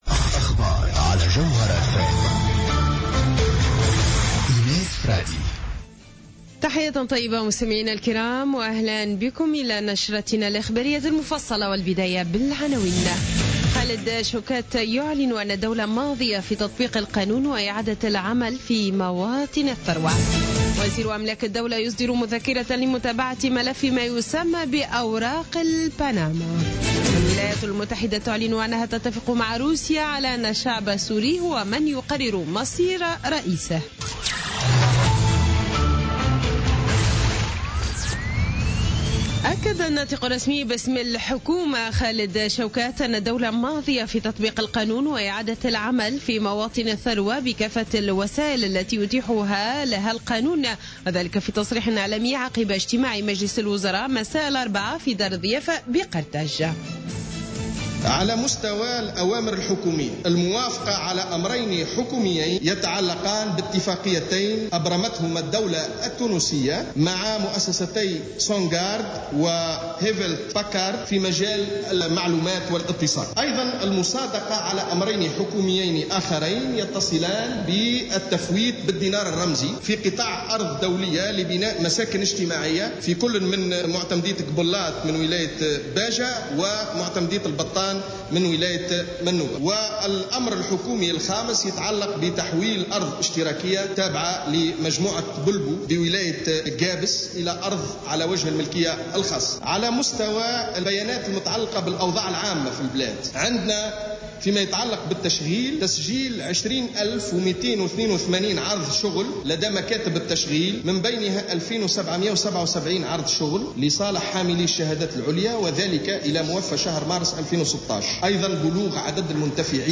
نشرة أخبار منتصف الليل ليوم الخميس 7 أفريل 2016